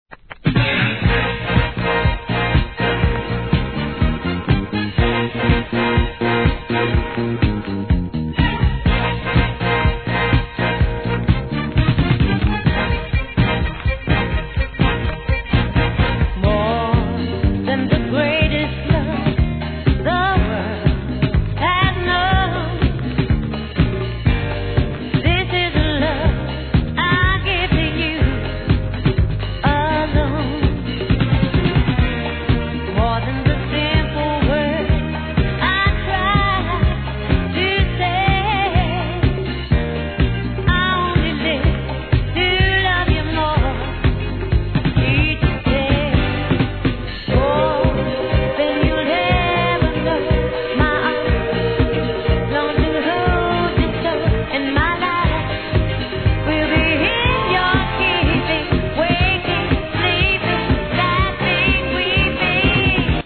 ¥ 990 税込 関連カテゴリ SOUL/FUNK/etc...